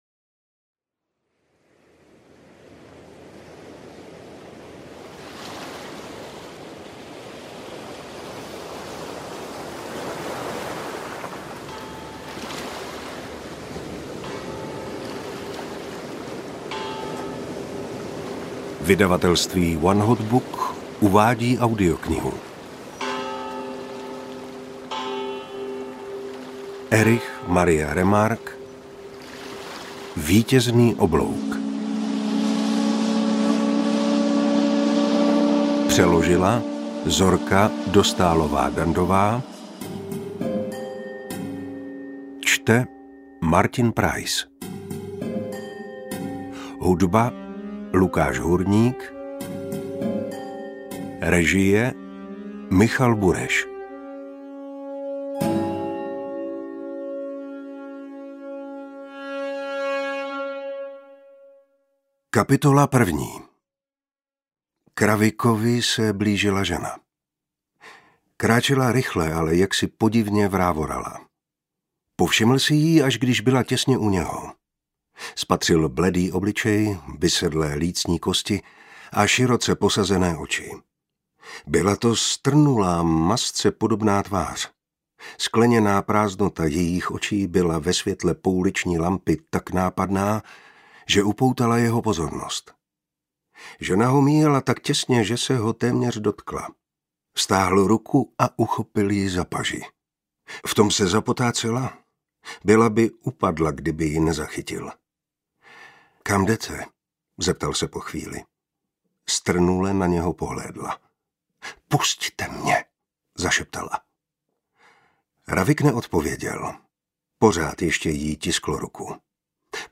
Interpret:  Viktor Preiss
AudioKniha ke stažení, 63 x mp3, délka 19 hod. 3 min., velikost 1029,0 MB, česky